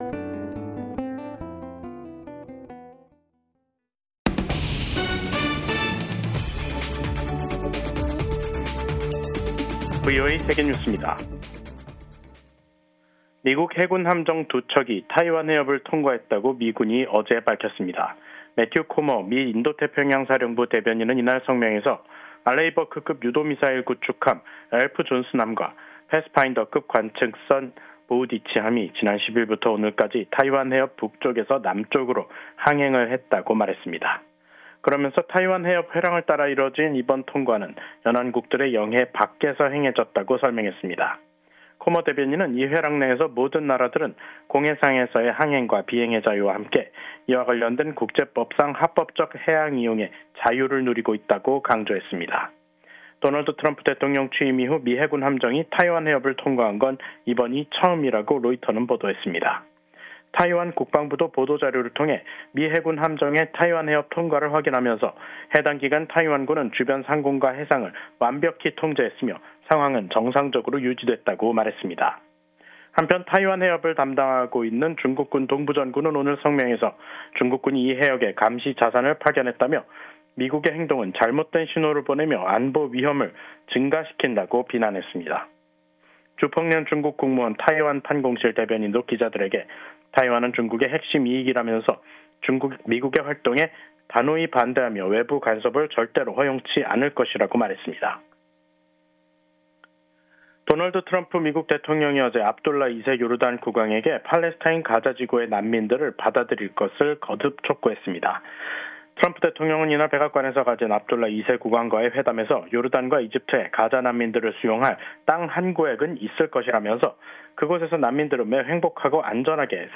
VOA 한국어 간판 뉴스 프로그램 '뉴스 투데이', 2025년 2월 12일 2부 방송입니다. 도널드 트럼프 미국 대통령이 또 다시 김정은 북한 국무위원장과의 정상회담을 추진할 것임을 시사했습니다. 미국인 여성이 북한 정보기술(IT) 인력의 위장 취업을 도운 혐의를 인정했다고 미 법무부가 밝혔습니다. 미국 연방수사국(FBI)이 공개 수배 중인 대북제재 위반자가 최근 급증 양상을 보이고 있습니다.